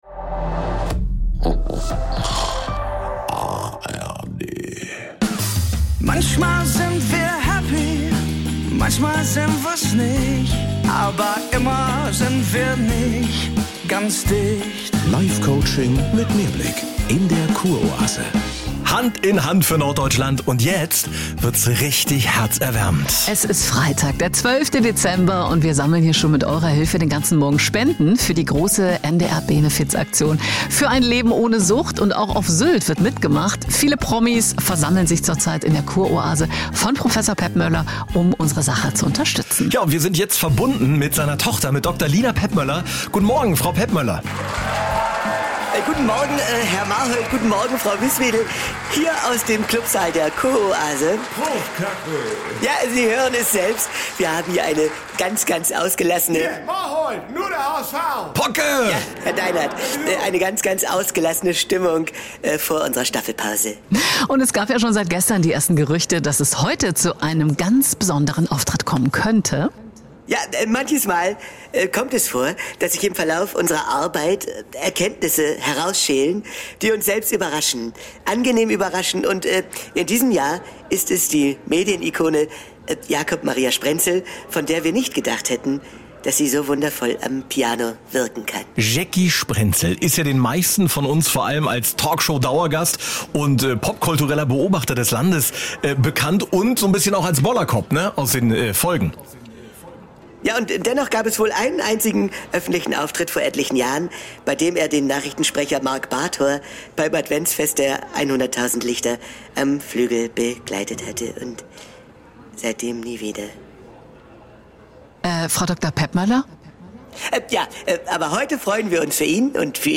In einer Live-Schalte, aus dem NDR 2 Morgen mit